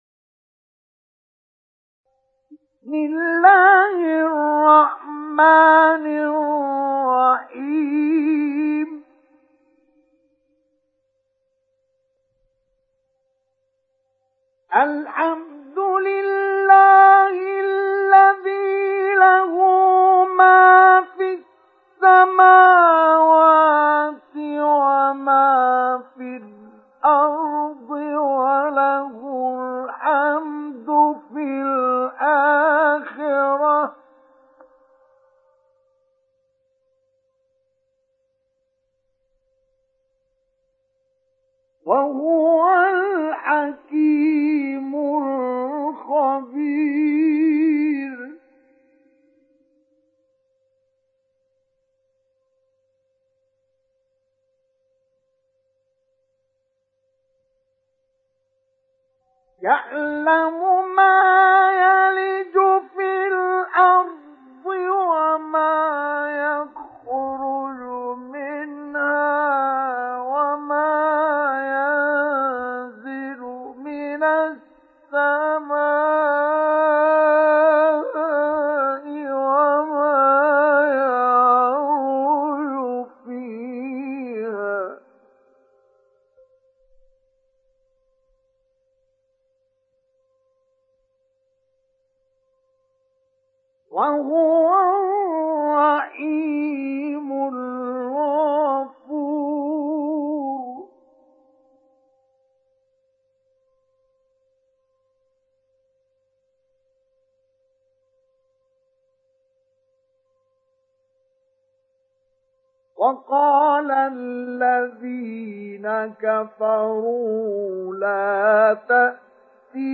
سُورَةُ سَبَإٍ بصوت الشيخ مصطفى اسماعيل